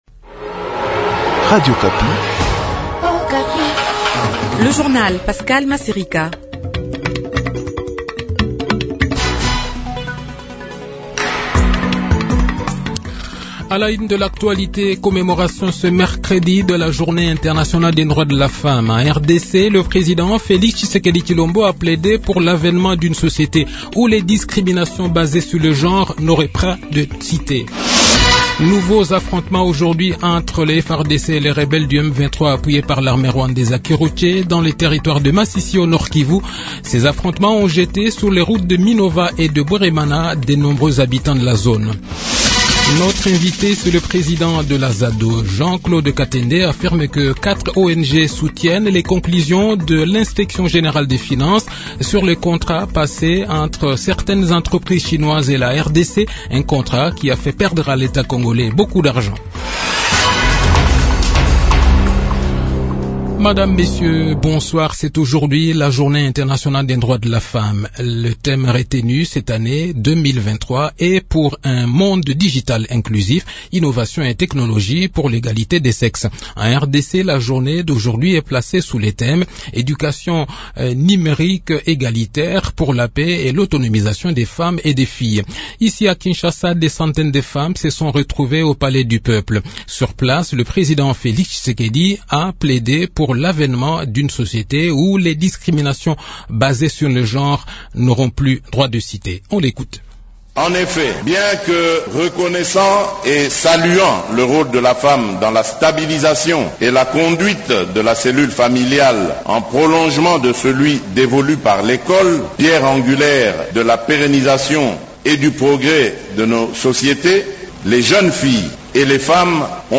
Journal Soir
Le journal de 18 h, 8 mars 2023